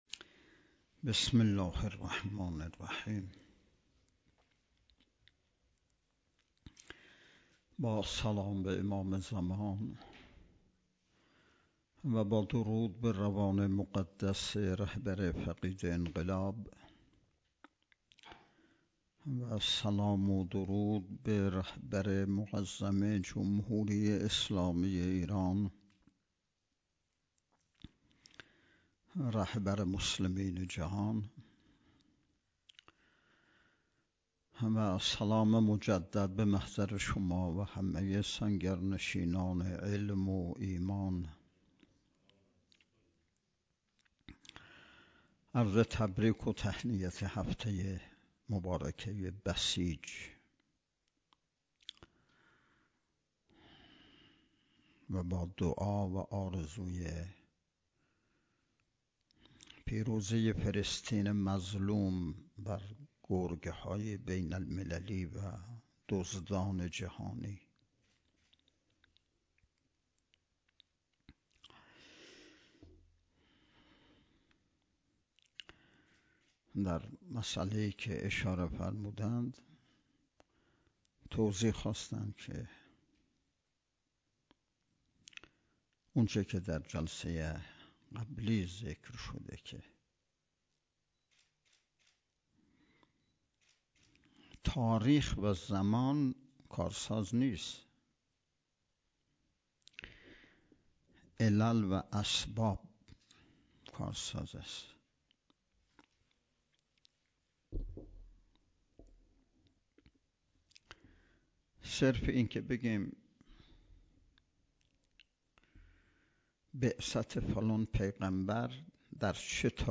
سومین نشست ارکان شبکه تربیتی صالحین بسیج با موضوع تربیت جوان مؤمن انقلابی پای کار، صبح امروز ( ۴ آبان) با حضور و سخنرانی نماینده ولی فقیه در استان، برگزار شد.